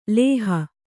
♪ lēha